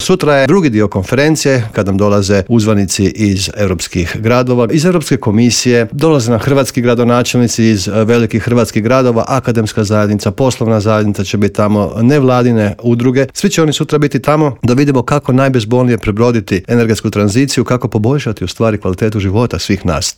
ZAGREB - Povodom međunarodne Konferencije "Zelena tranzicija pokreće europske gradove" predsjednik Gradske skupštine Grada Zagreba Joško Klisović gostovao je Intervjuu Media servisa.